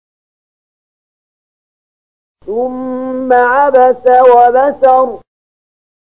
074022 Surat Al-Muddatstsir ayat 22 bacaan murattal ayat oleh Syaikh Mahmud Khalilil Hushariy: